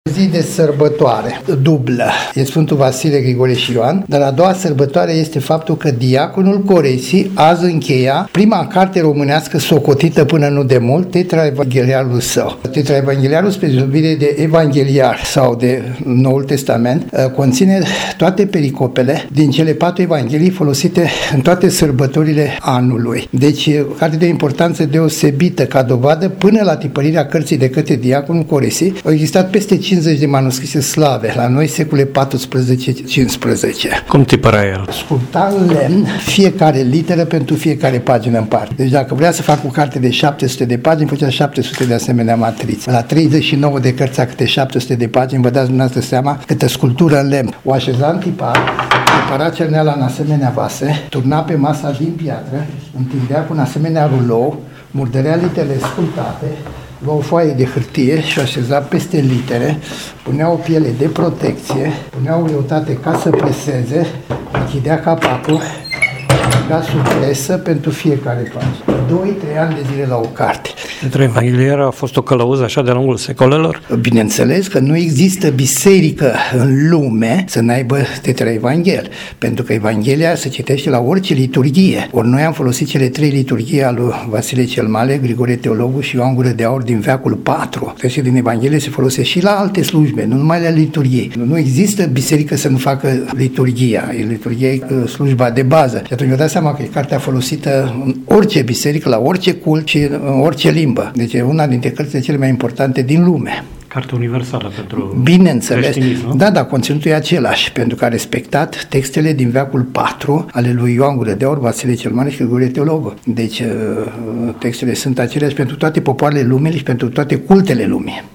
la tiparnita diaconului Coresi, la prima Scoala Romaneasca, din Scheii Brasovului, unde a tiparit Coresi lucrarea de capatai TETRAEVANGHELIARUL si ne a raspuns la intrebarile legate de aceasta lucrare